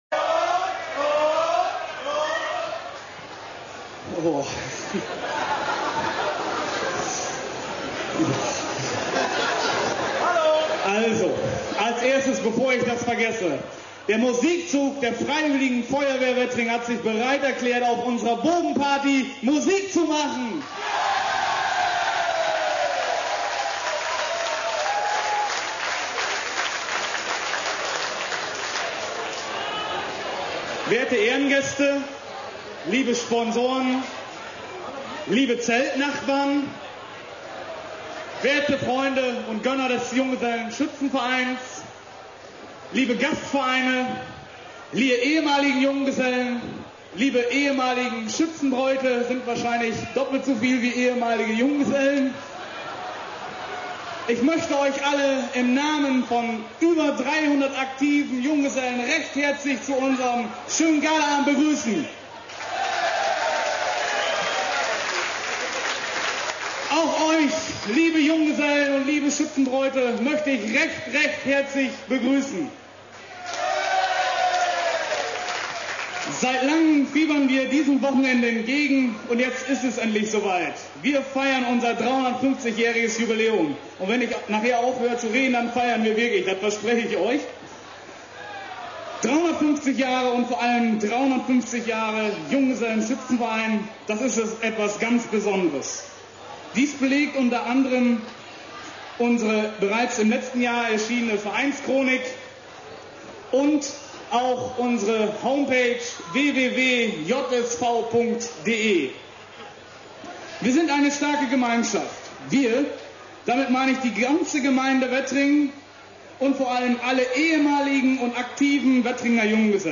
Rede des 1.